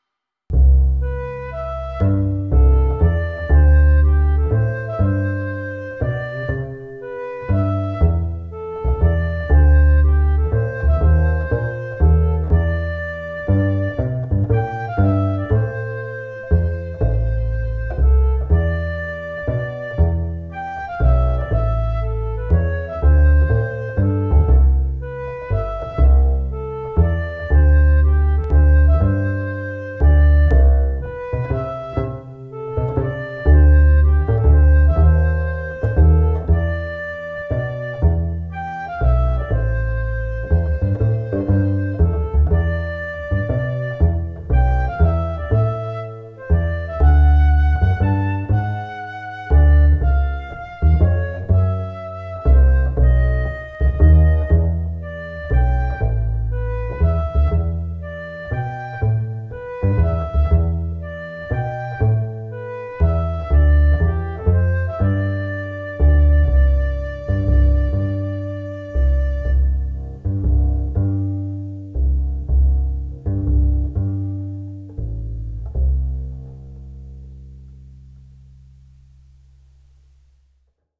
[♪] Blowing Bubbles on the Roof MIDI Flute & Bass '21.05.08 宅録
曲は普通のワルツを作ろう、と思って2017年頃に作った曲です。